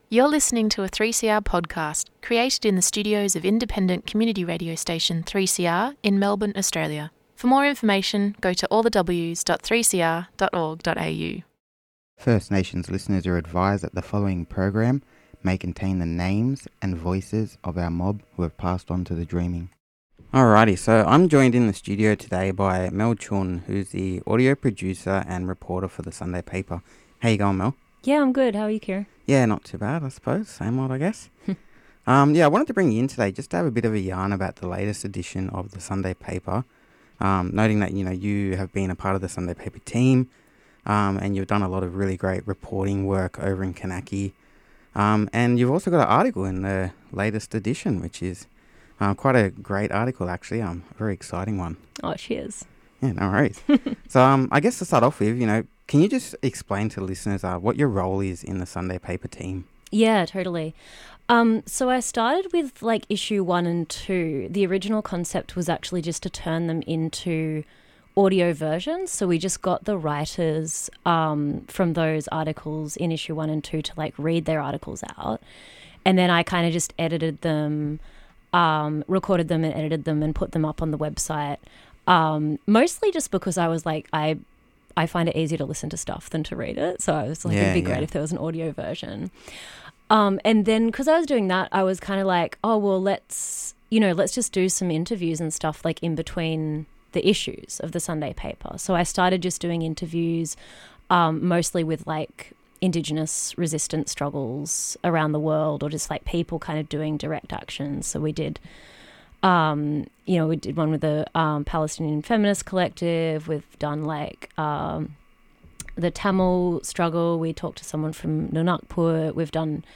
Current affairs